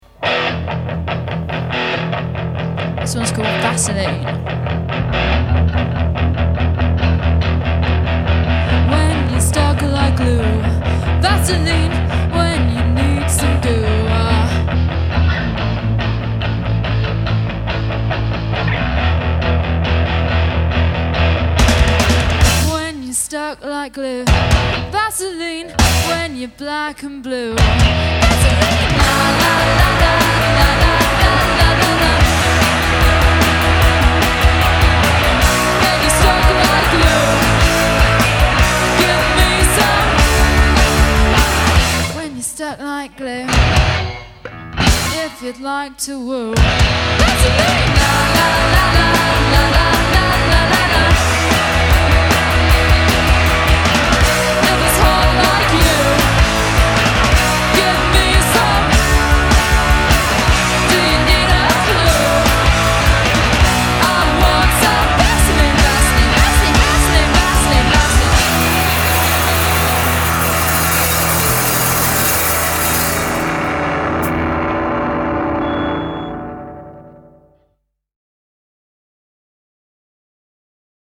Today we have a live recording of the song